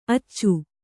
♪ accu